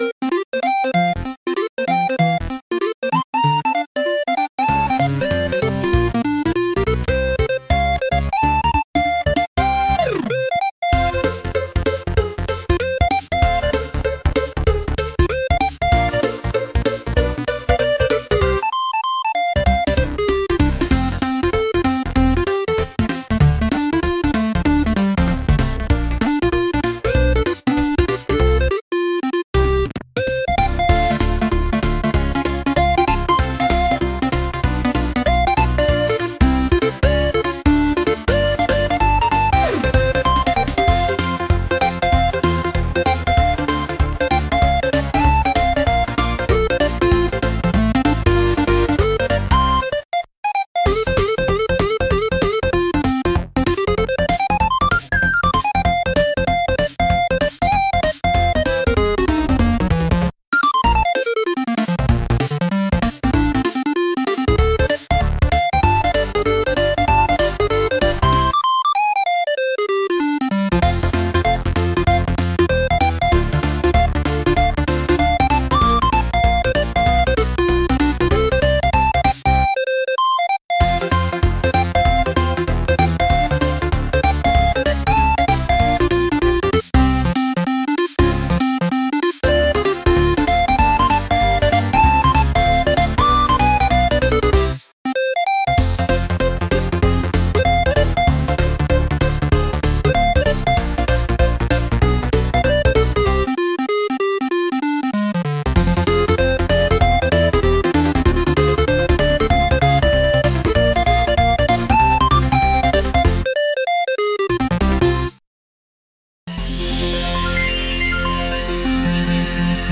偶然のふりして一緒に登校したいようなピコピコ8bitサウンドです。